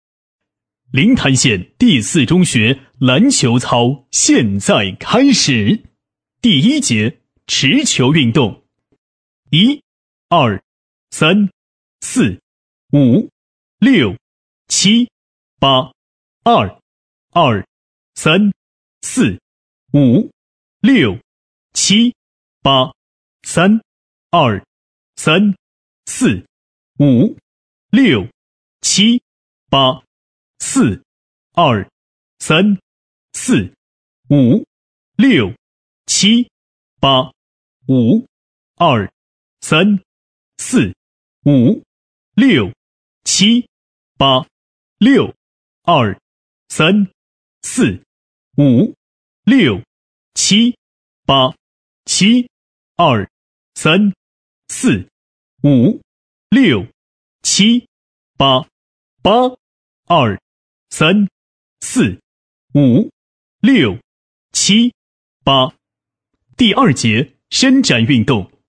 【男14号广播操】第四中学篮球操
【男14号广播操】第四中学篮球操.mp3